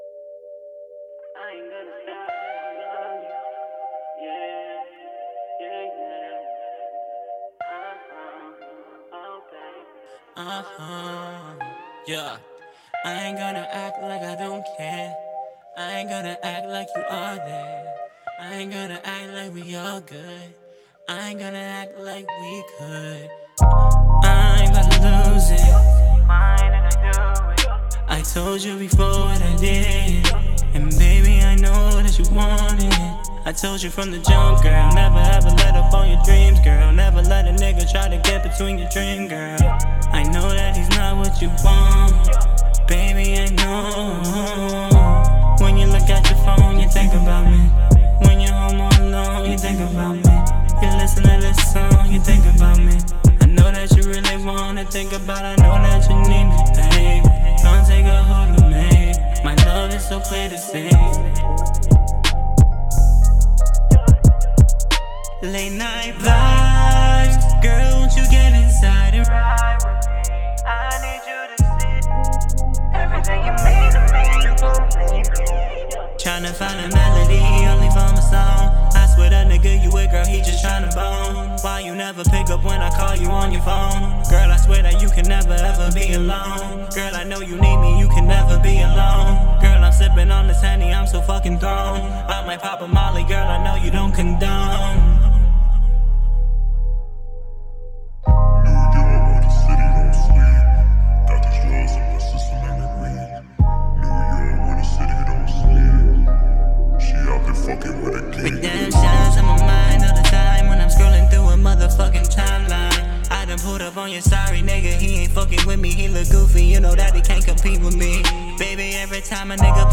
My second Mix - R&B/HipHop song called "Alone"
Hey there a month ago or so I posted a few parts of a song I was working on and got outstanding feedback from you guys on what to work on what to change etc. I have spent some time learning my DAW and how to get the vocal into the mix,master etc and today I come to you guys with this.
Everything was done on a rode NT1 through a focusrite 2i2.